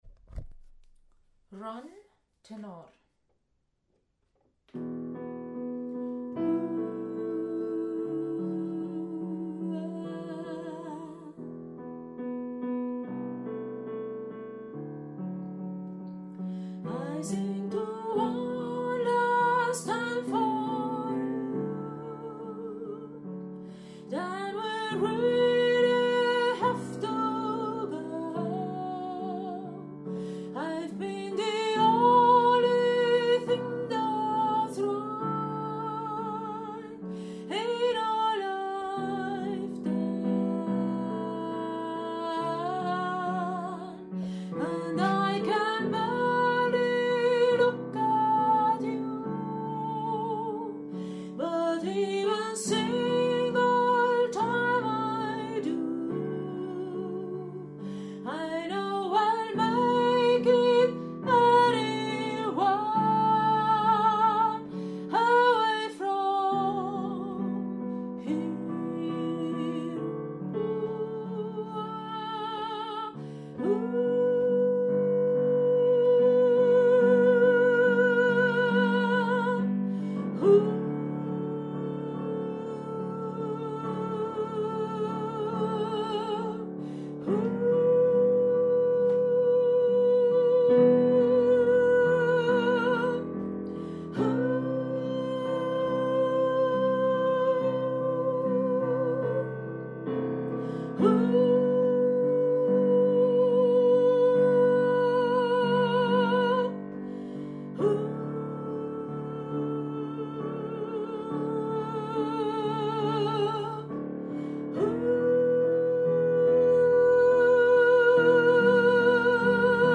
Run – Tenor
Run-Tenor.mp3